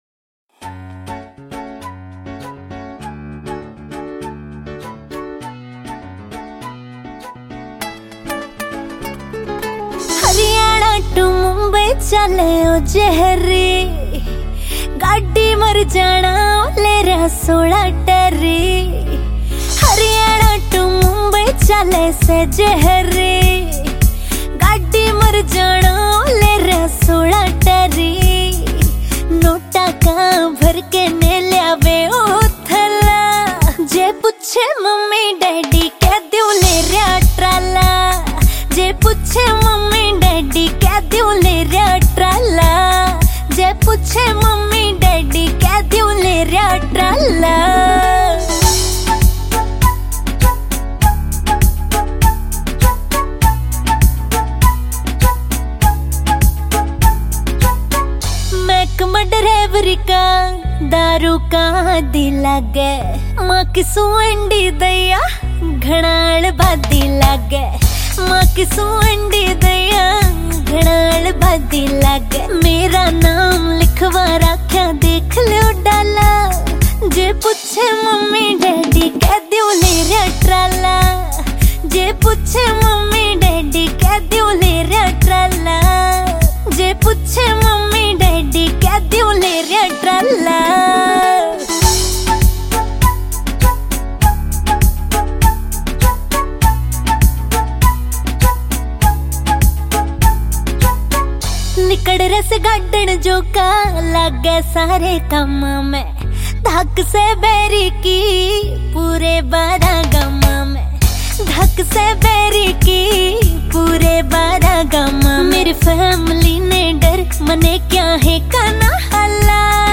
Genre Haryanvi Song